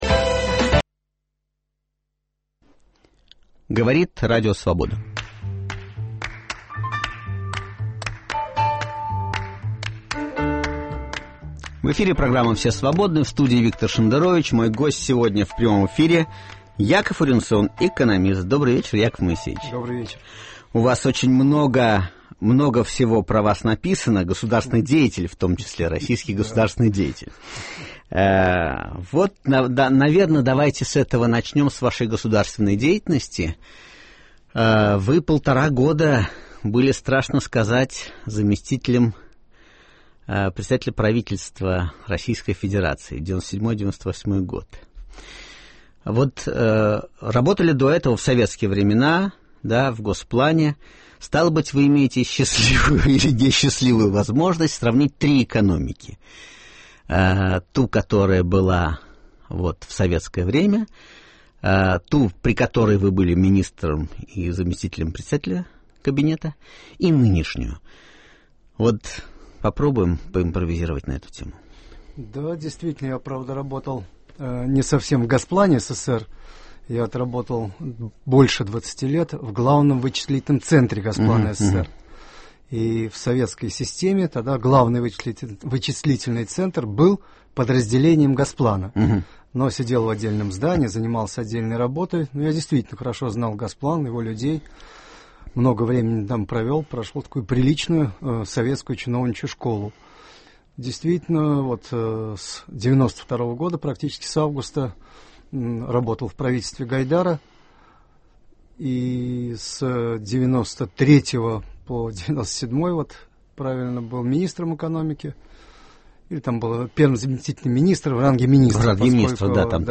Свободный разговор на свободные темы. Гостей принимает Виктор Шендерович, который заверяет, что готов отвечать на любые вопросы слушателей, кроме двух: когда он, наконец, уедет в Израиль и сколько он получает от ЦРУ?